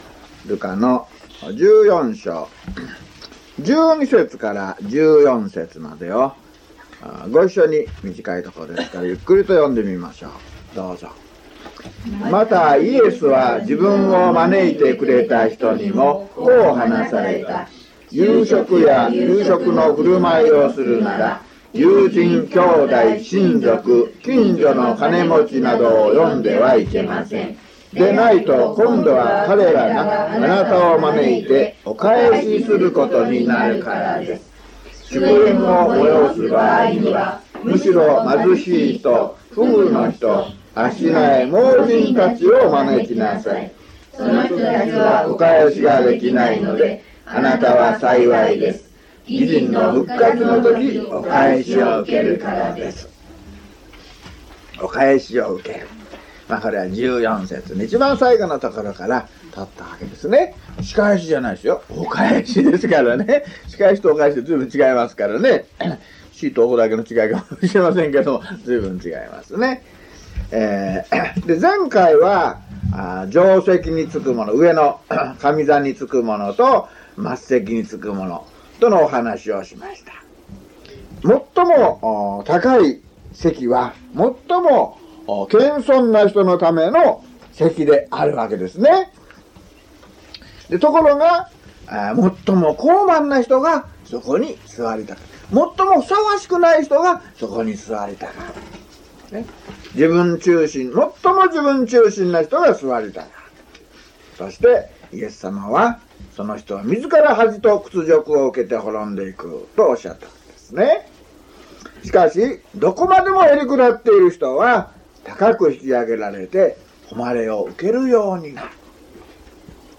luke106mono.mp3